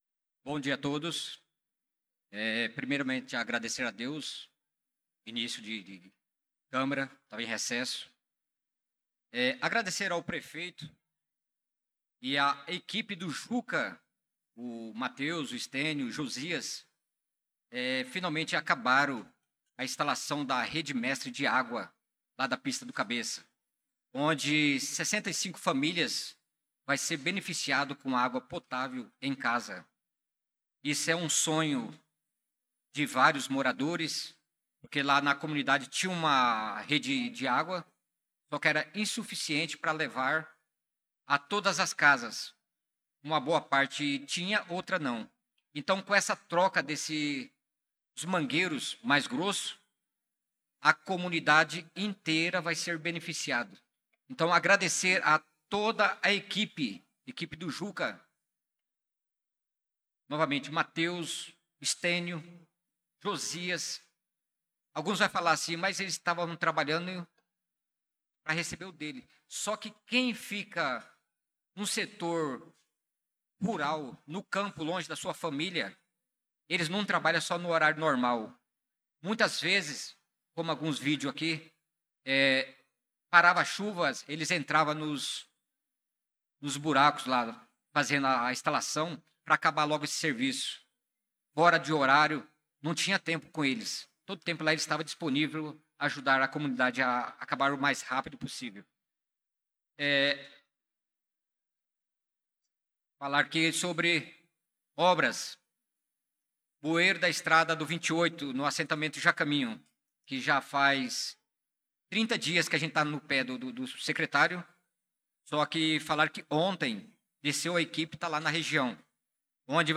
Pronunciamento do verador Naldo da Pista na Sessão Ordinária do dia 04/02/2025